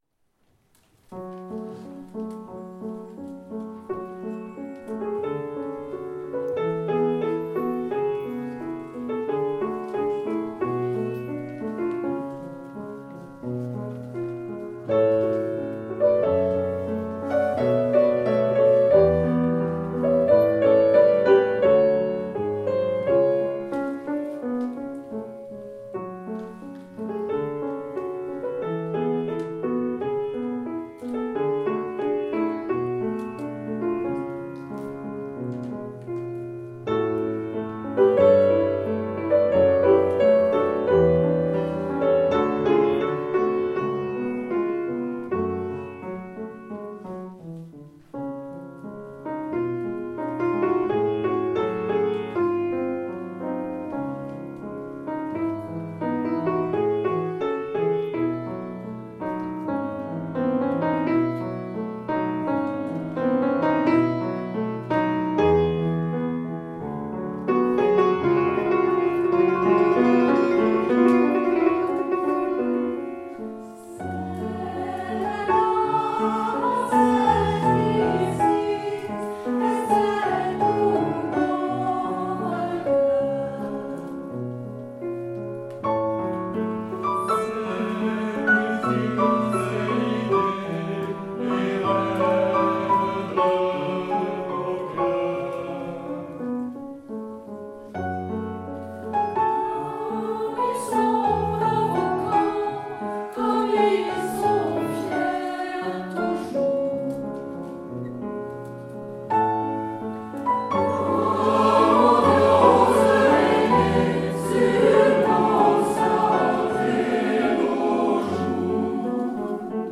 Concert Centre Alfred-de-Vigny de Voisins le Bretonneux 6 juin 2015